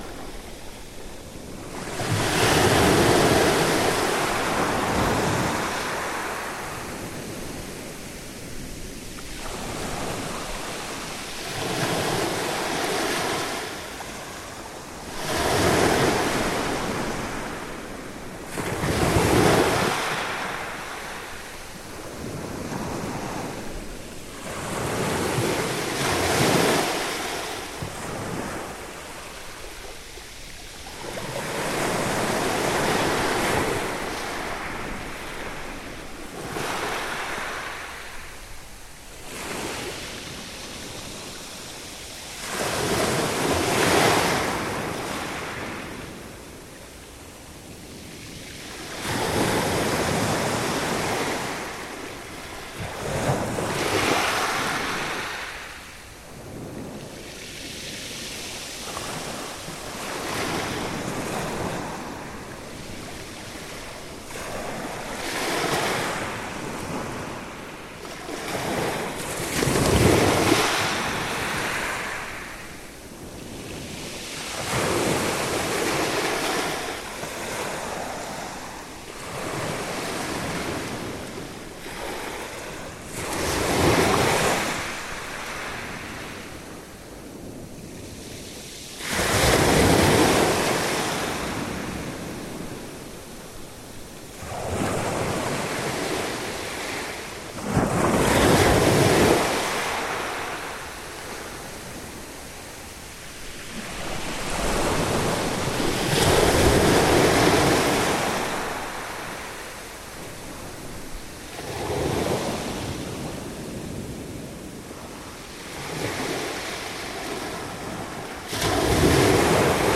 December evening waves on the South Beach at Greystones, Co Wicklow, Ireland
Waves, Sea, Ireland